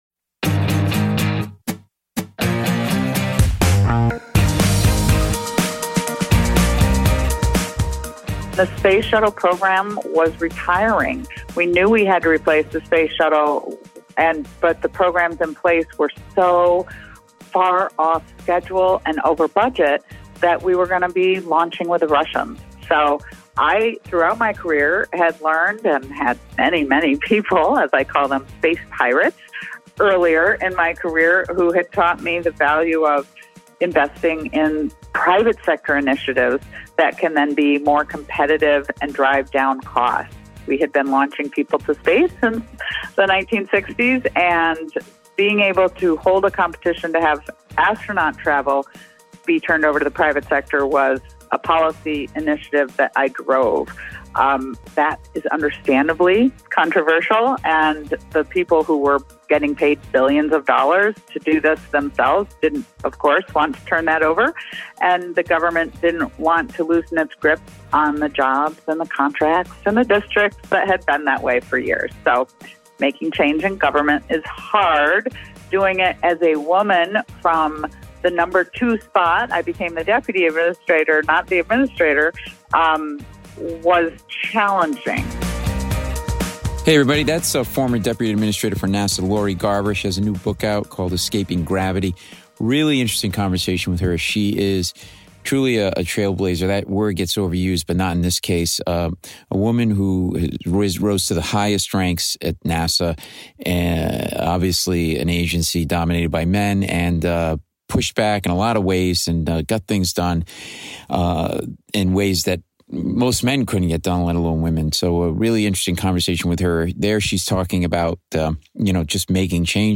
NASA's Artemis Moon Program has been launched with the plan to land the first woman and first person of color on the Moon so this is the PERFECT time for this interview with Lori Garver, a trailblazer who pioneered a place for women at the highest levels of NASA.